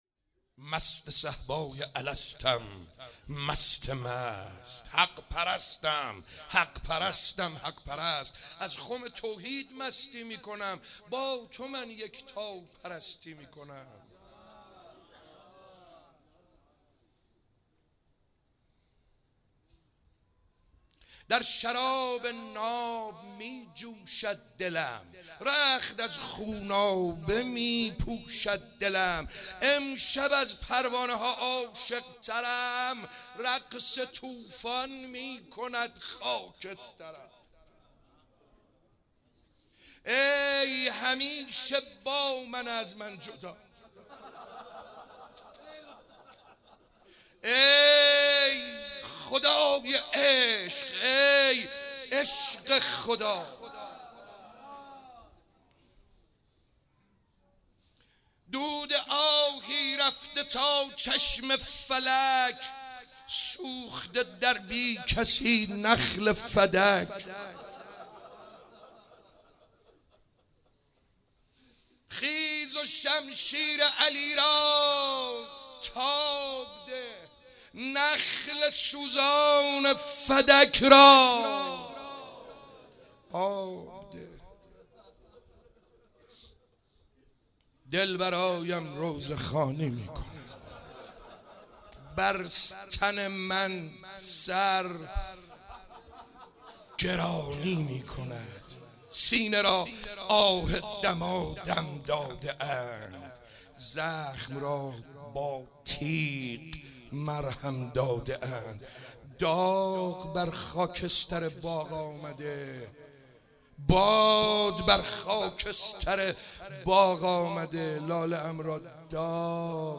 شب 30 ماه مبارک رمضان 96 - شعرخوانی - مست صهبای الستم مست مست